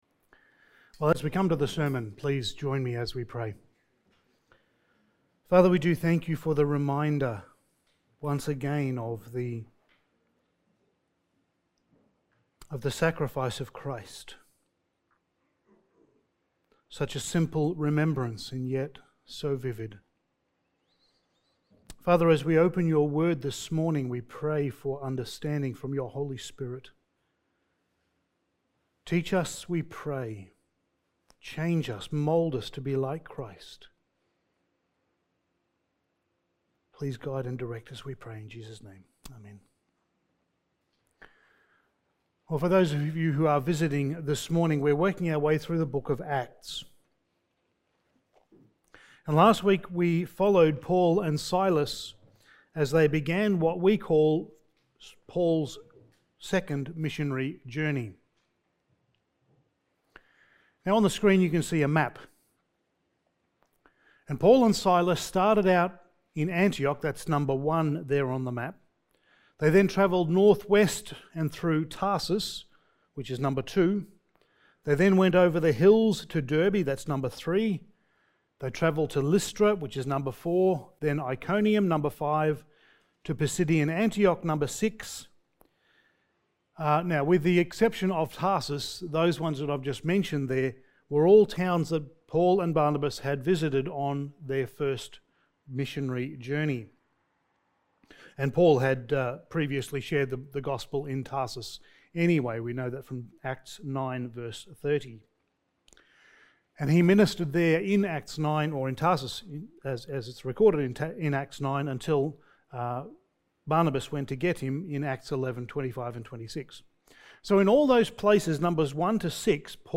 Passage: Acts 16:13-40 Service Type: Special Event